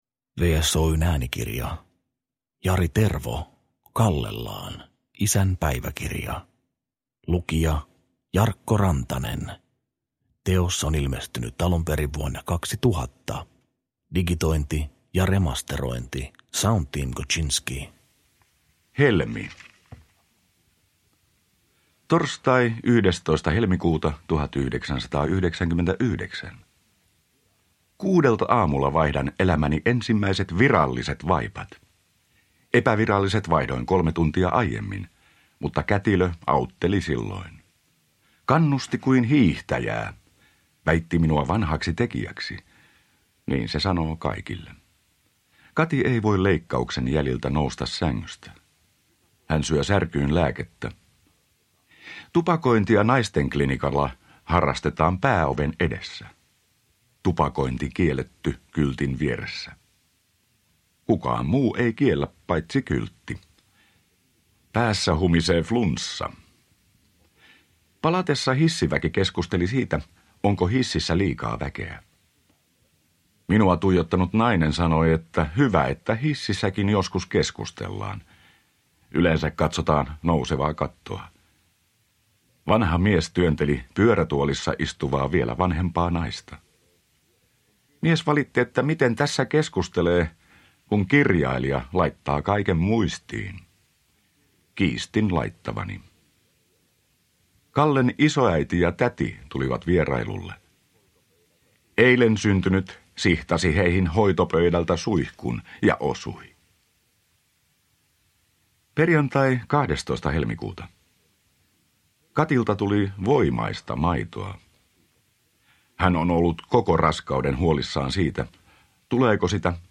Kallellaan – Ljudbok – Laddas ner